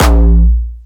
Jumpstyle Kick Solo
6 A#1.wav